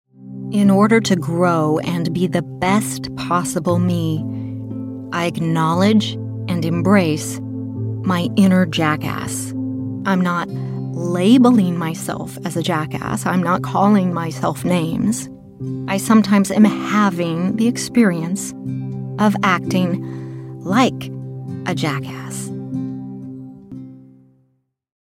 This is a powerful spoken word journey of reflection to assist you in resolving and integrating the shadow self.